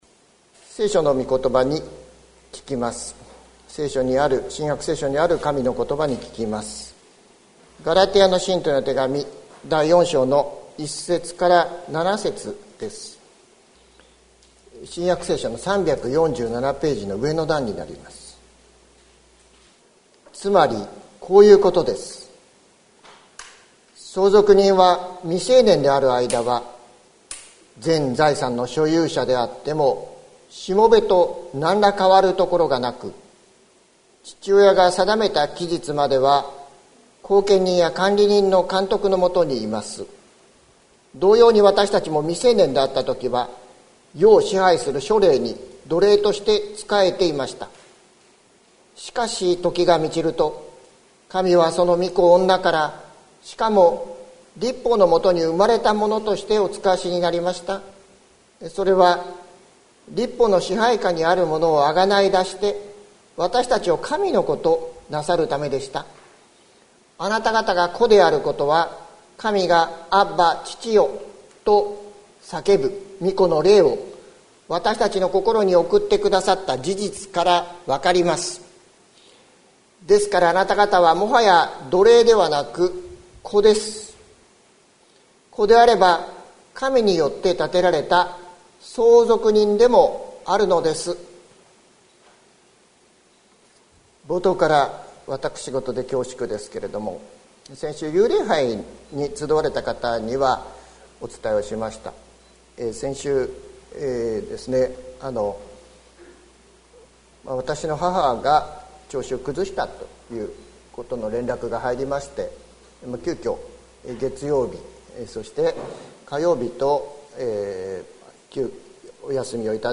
2021年06月28日朝の礼拝「アッバ、父よ」関キリスト教会
説教アーカイブ。